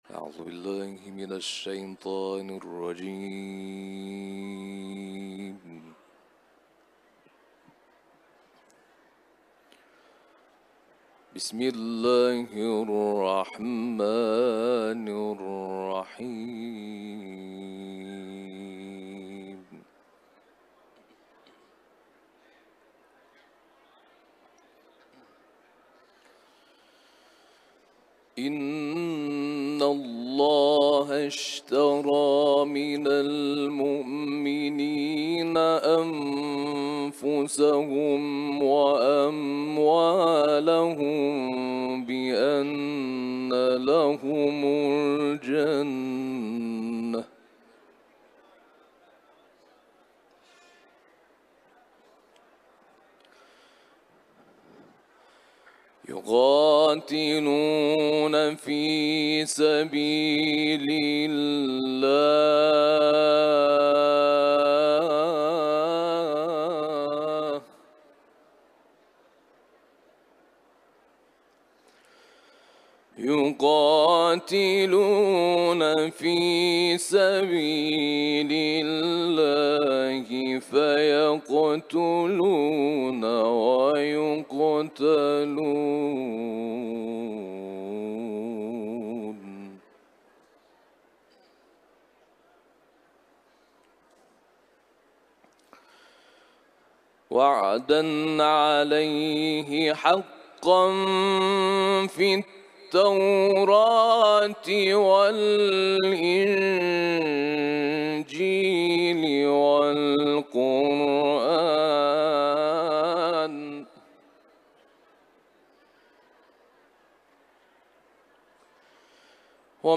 سوره توبه ، تلاوت قرآن